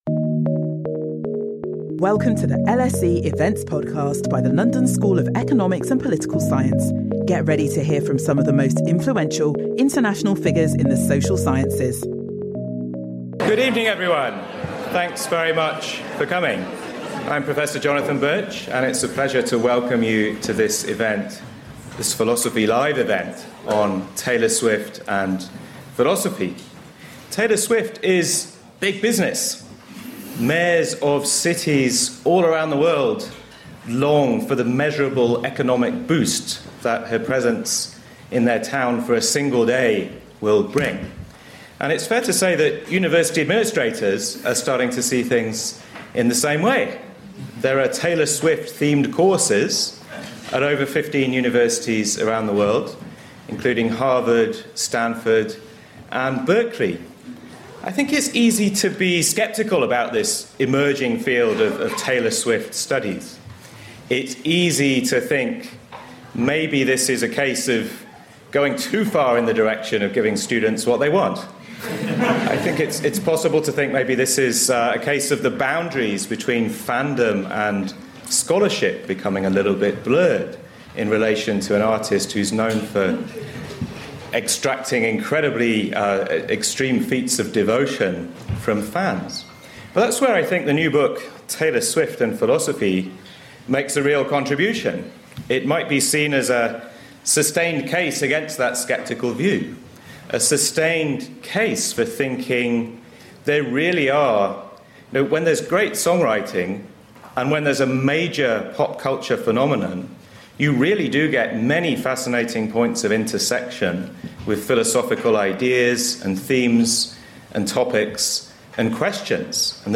This panel event will launch the book with a discussion of its main themes.